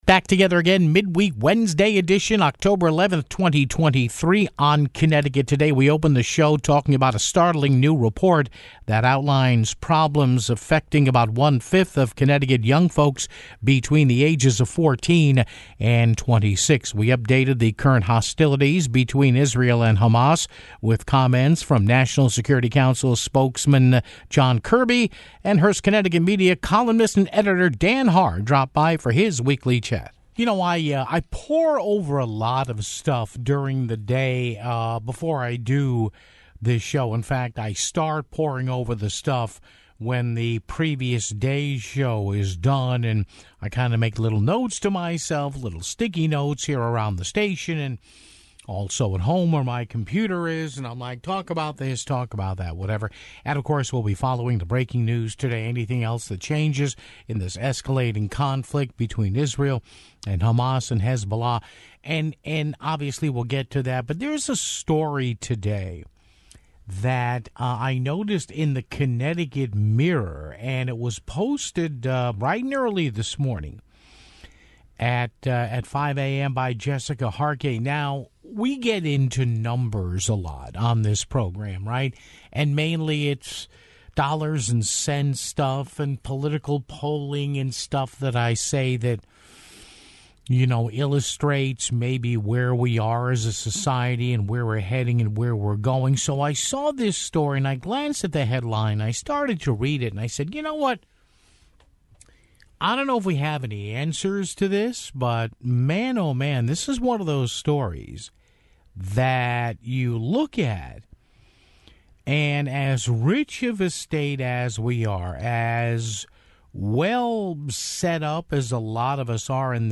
We carried a portion of National Security Council spokesman John Kirby's news conference regarding the escalating violence in the Middle East (13:25).